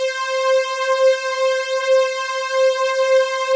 ZG3 SYN STR.wav